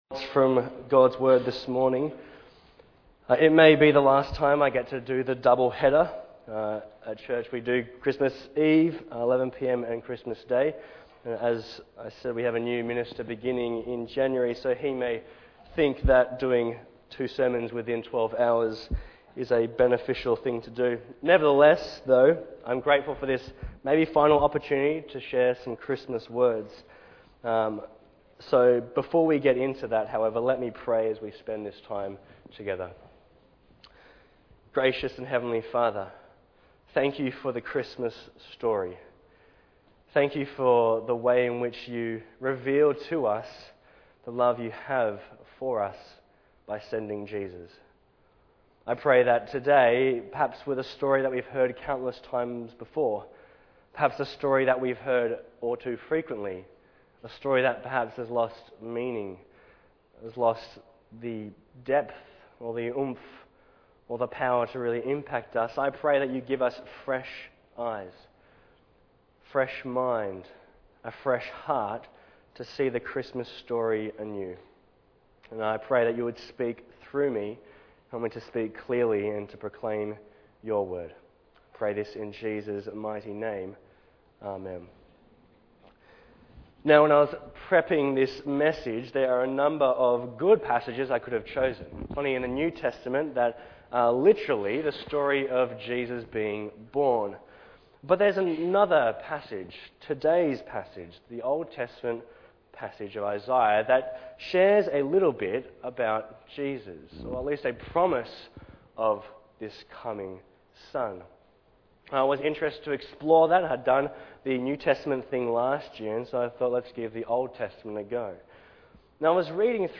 Bible Text: Isaiah 9:2-7 | Preacher